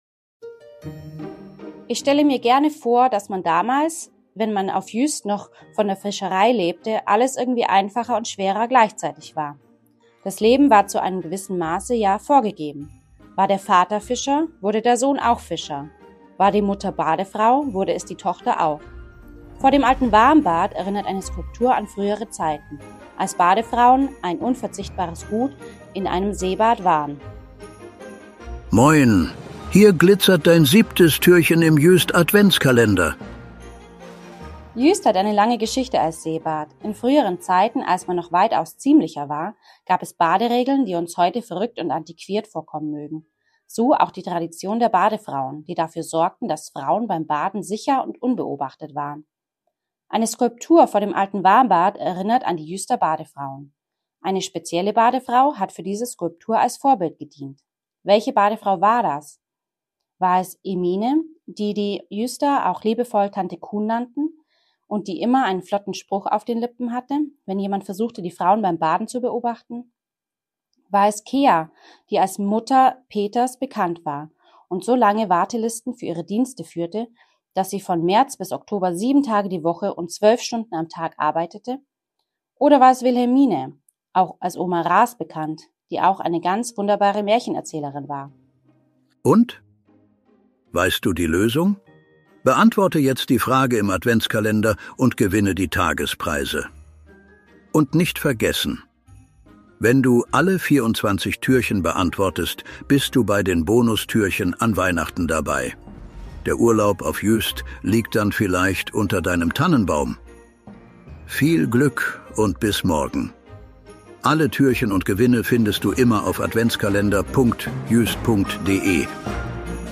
guten Geistern der Insel Juist, die sich am Mikro abwechseln und